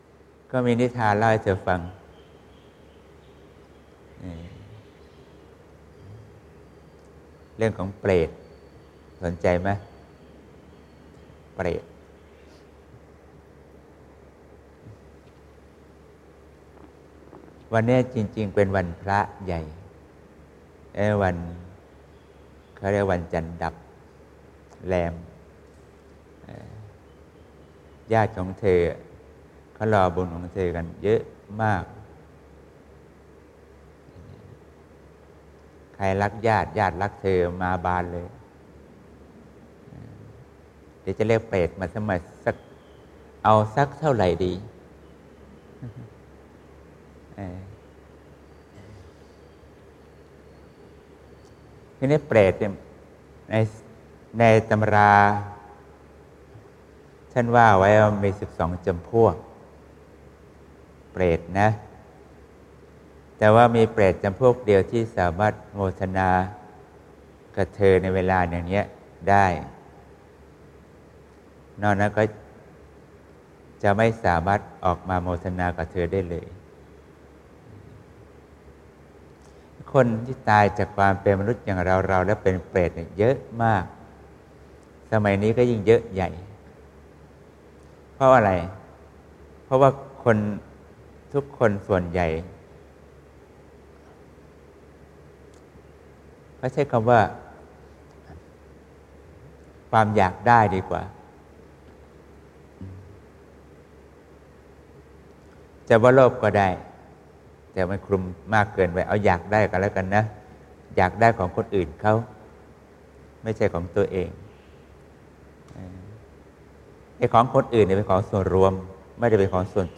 ปฏิบัติให้เกิดผลด้วยการเพียรใคร่ครวญให้จิตเป็นสุขจนเกิดความสงบ(ปกิณกะธรรม ๑๖ เม.ย. ๖๔) (เปิดเสียงบันทึก - เสียงธรรมที่แสดงไว้เมื่อวันที่ ๑๙ เมษายน ๒๕๖๒)